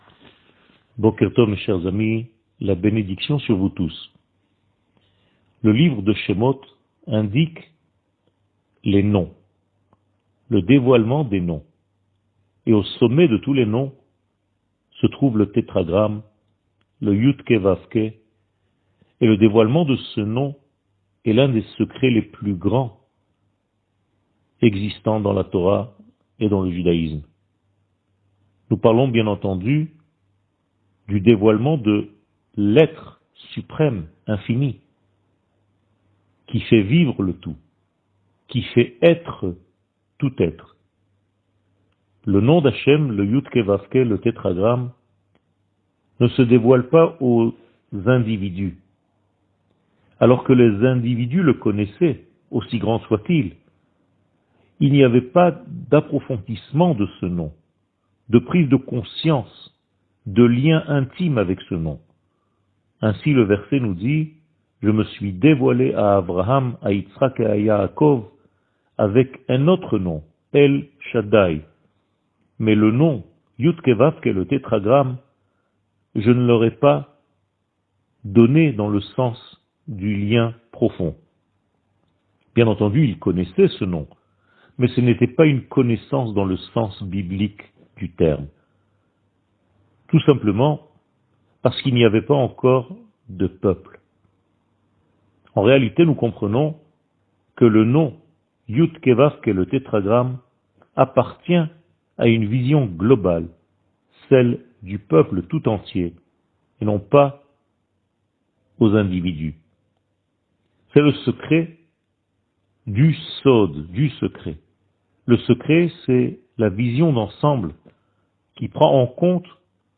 שיעור מ 04 ינואר 2022